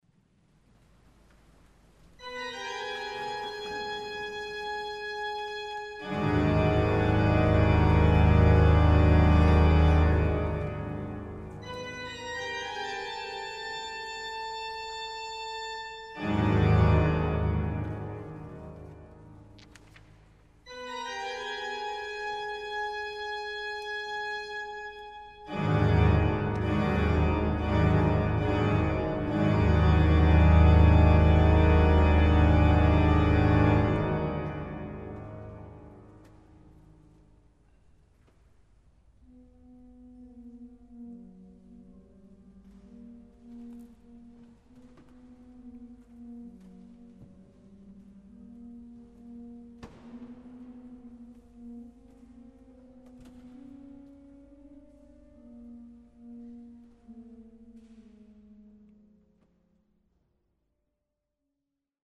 Alle Glocken der Welt schlagen Alarm.
Orgel